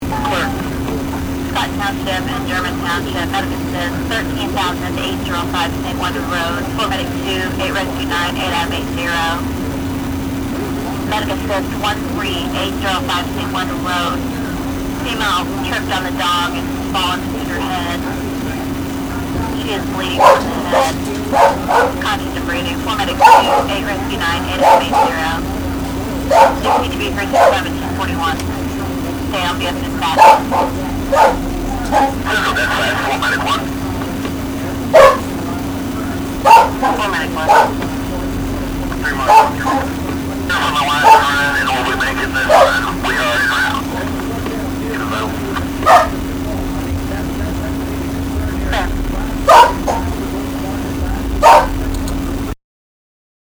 Tags: conversation uncensored funny celebrities comedy